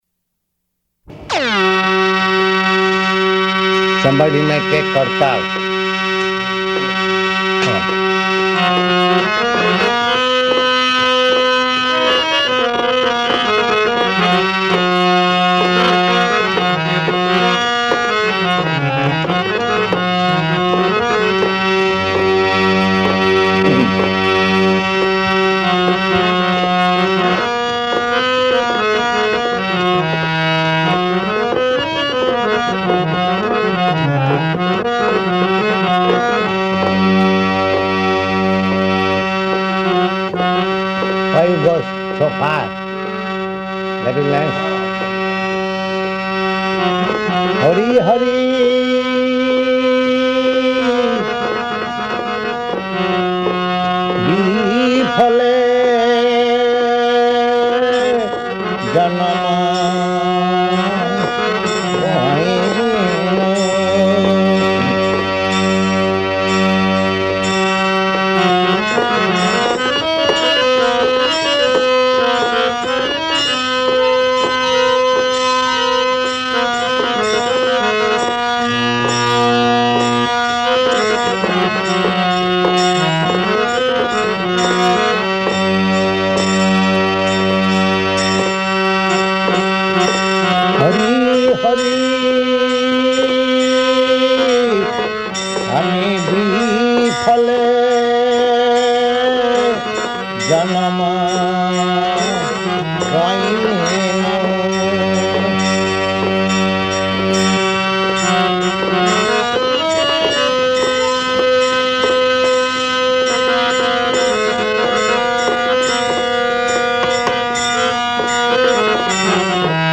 Bhajan & Purport to Hari Hari Biphale